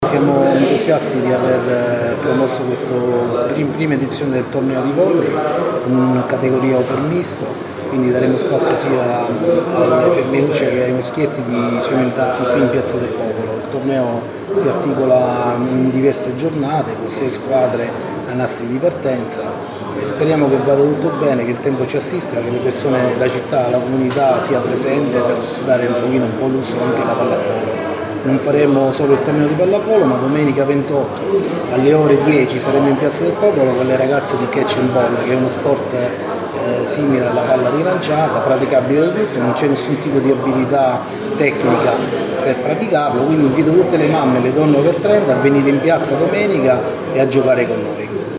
Ieri pomeriggio, presso la sala De Pasquale del Comune, la conferenza stampa di presentazione della prima edizione del Torneo di Pallavolo “Città di Latina”, che prenderà il via oggi con il Villaggio Europeo dello Sport.